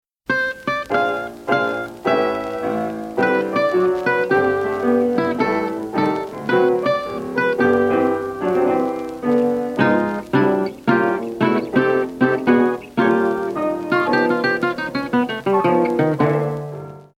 The following audio files have been created using tracks from Open Music Archive.